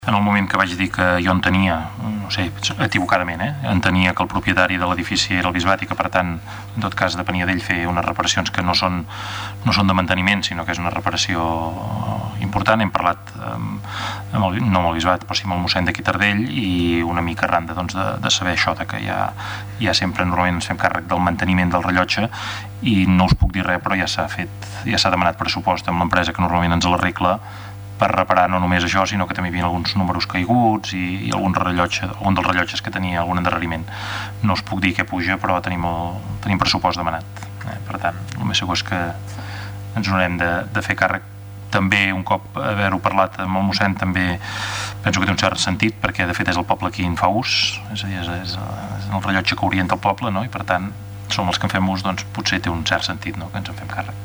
Serà l'Ajuntament de Taradell qui, finalment, es farà càrrec d'arreglar el vidre trencat d'un dels rellotges del campanar de Taradell. Així ho va manifestar en el ple d'aquest dijous